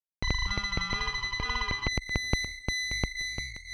• glass smear synth seq Em.wav
Sound designed using multiple effects processors and gaters, applied on modular gear (Korg)